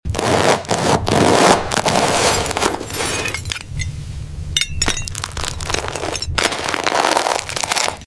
A loaf of bread and a slice of pizza have a crunchy, glass-like exterior but a soft, realistic inside. Experience the oddly satisfying contrast of a sharp, glassy crack followed by a steamy bread crumb reveal and a gooey cheese pull.